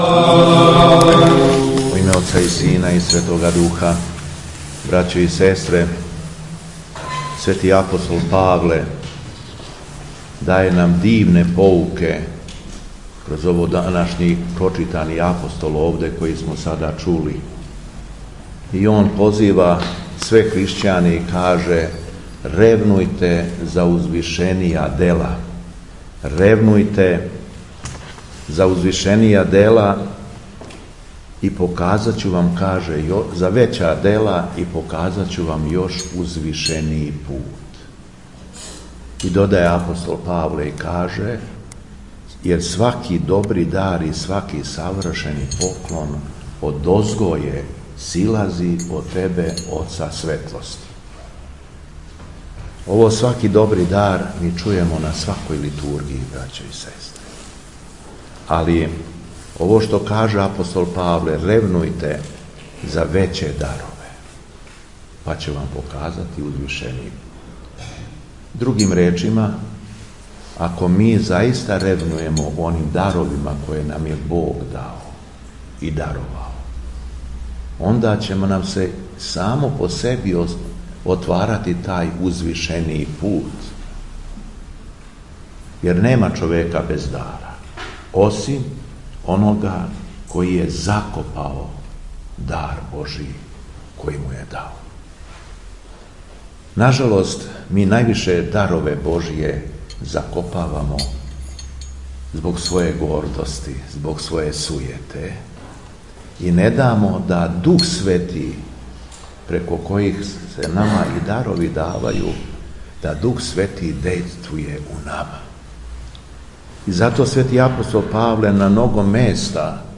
Беседа Његовог Преосвештенства Епископа шумадијског г. Јована
После прочитаног јеванђелског зачала Преосвећени Владика се обратио верном народу беседом: